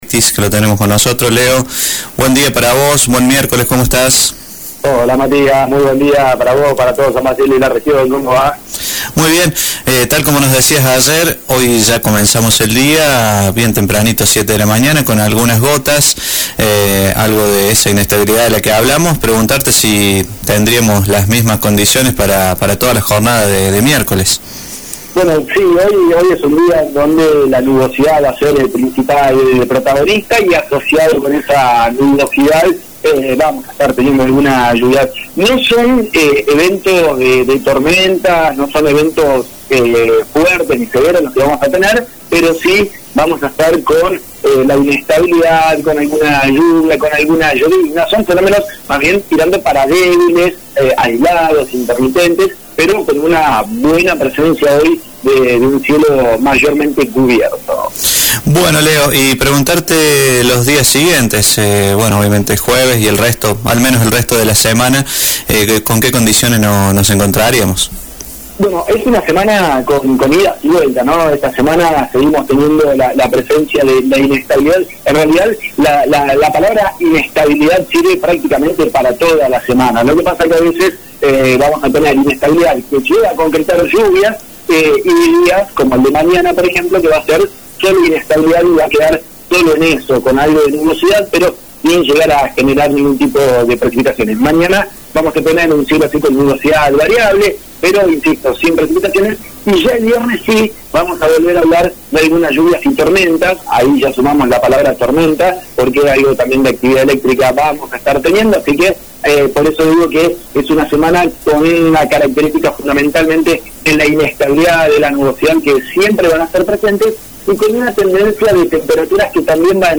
PRONOSTICO-MIERCOLES-30.mp3